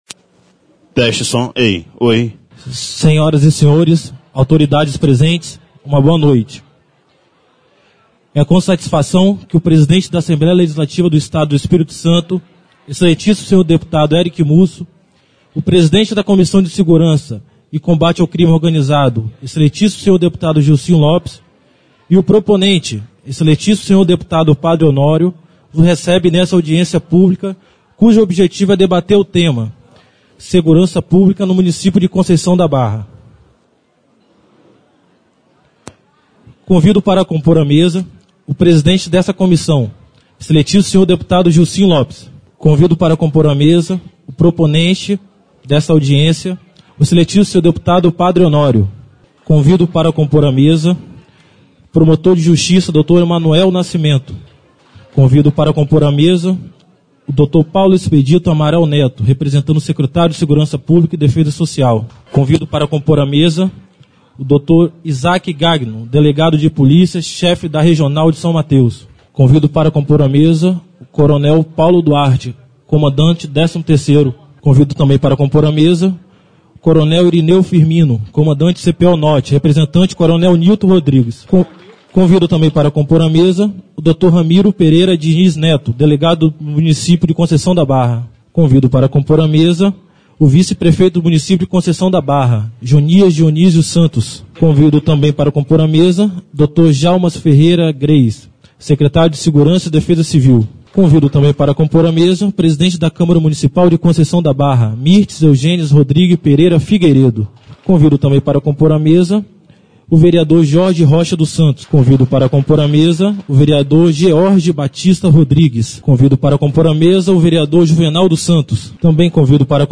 AUDIÊNCIA PÚBLICA TEMA SEGURANÇA 25 DE MAIO DE 2017